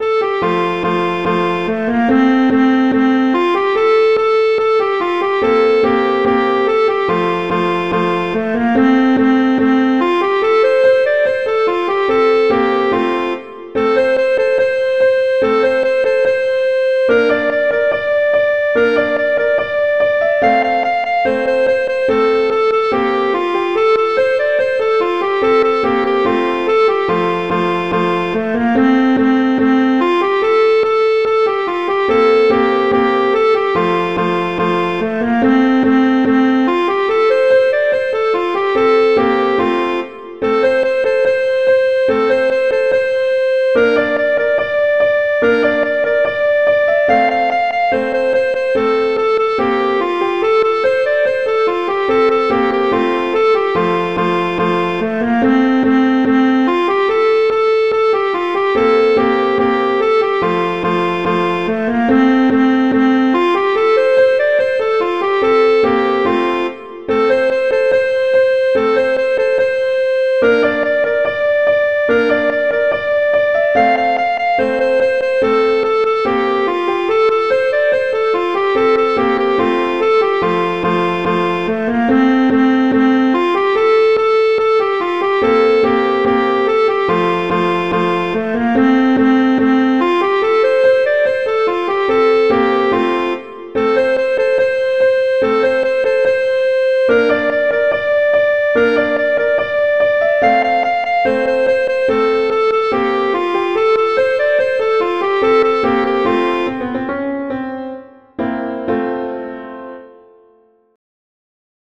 Instrumentation: clarinet & piano
arrangements for clarinet and piano